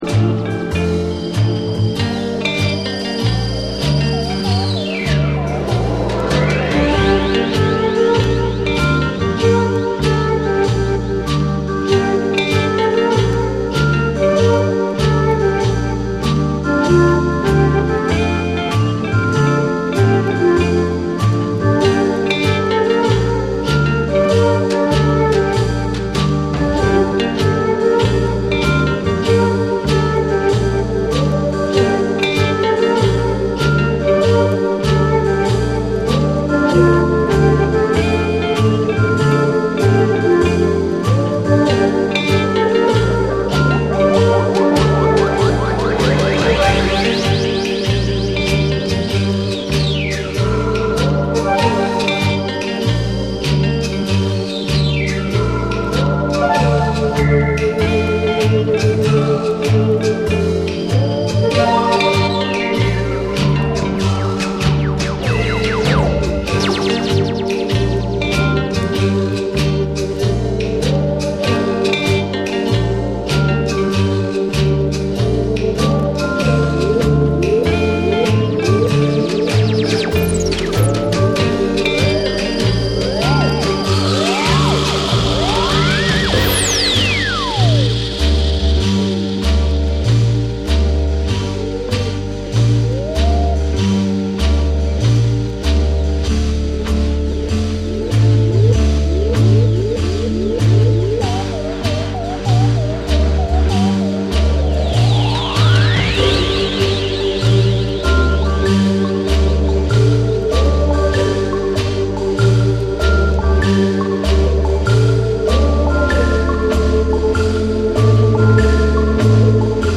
サントラ/ライブラリー好きから、エレクトロニカ／ダウンテンポのリスナーまで幅広くおすすめ。
BREAKBEATS